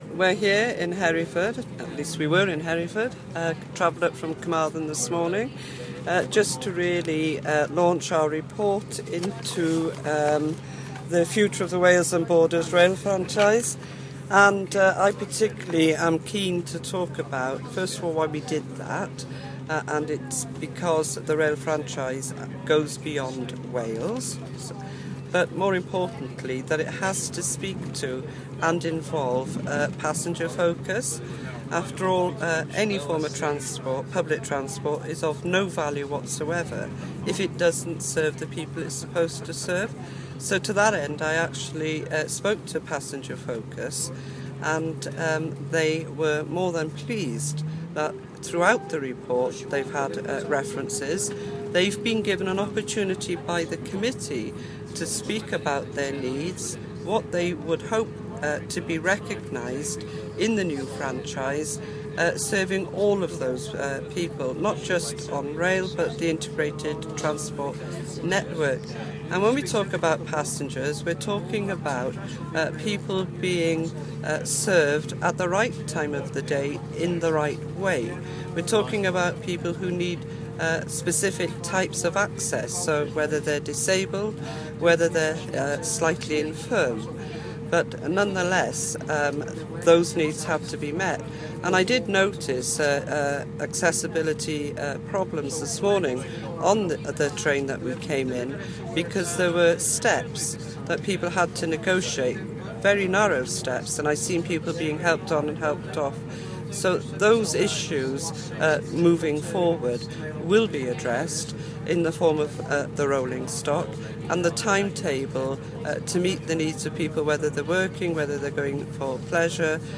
Joyce Watson AM talks about the Enterprise and Business Committee report on the Wales and Borders rail franchise / Joyce Watson AC yn trafod adroddiad y Pwyllgor Menter a Busnes ar Fasnachfraint Rheilffyrdd Cymru a'r Gororau